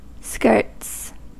Ääntäminen
Synonyymit pan basconade euskara euscarien euskarien basquais eskuara euskera Ääntäminen France: IPA: /bask/ Haettu sana löytyi näillä lähdekielillä: ranska Käännös Konteksti Ääninäyte Adjektiivit 1.